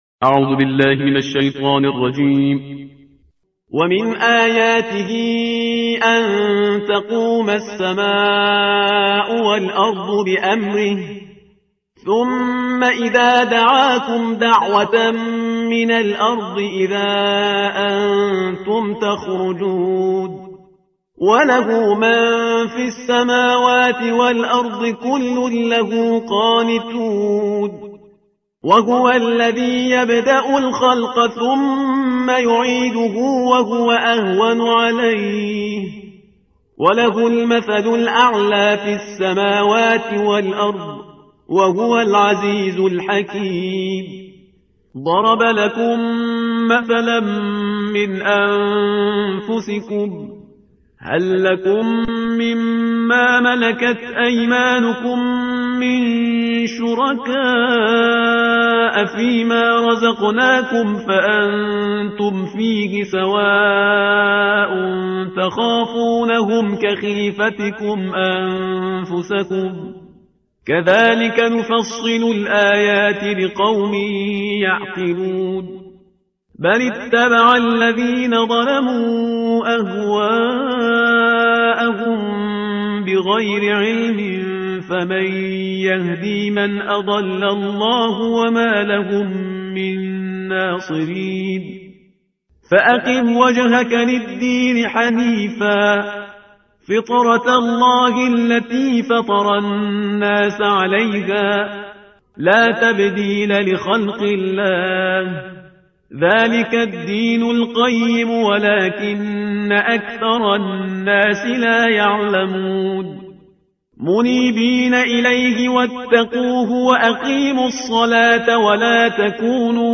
ترتیل صفحه 407 قرآن با صدای شهریار پرهیزگار